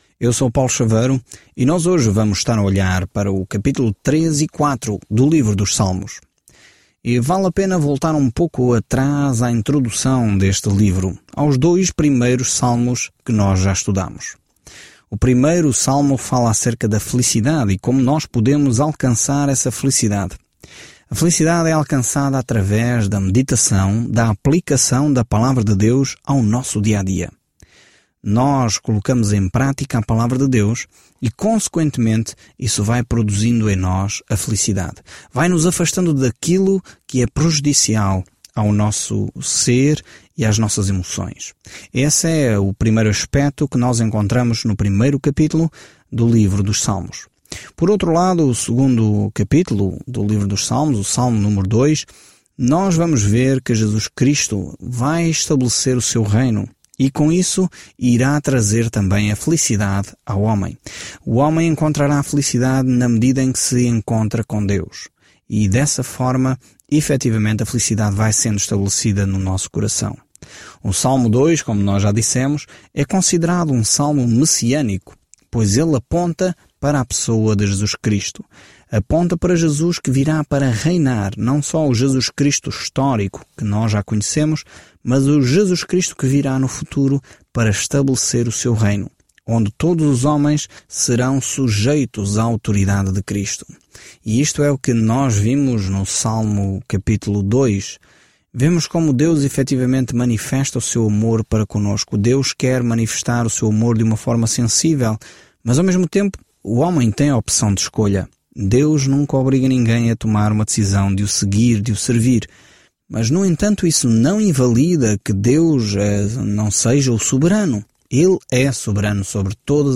Viaje diariamente pelos Salmos enquanto ouve o estudo de áudio e lê versículos selecionados da palavra de Deus.